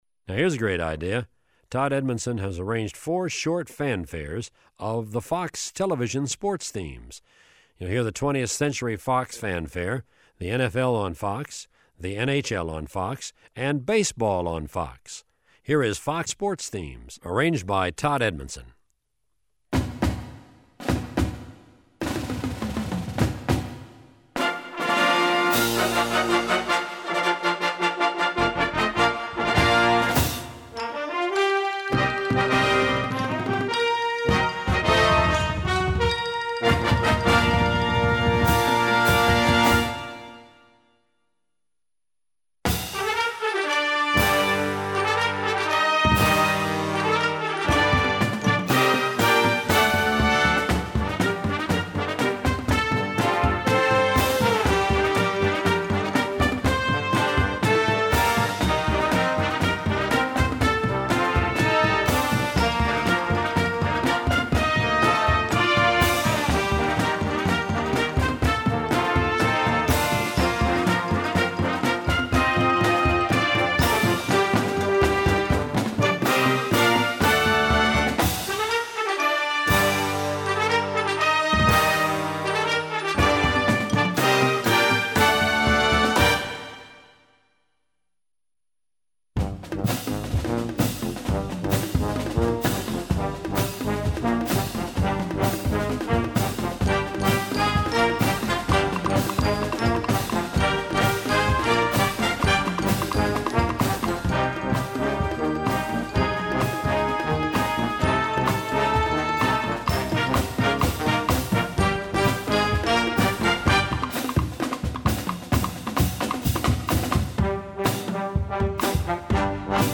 Gattung: Marching Band Series
Besetzung: Blasorchester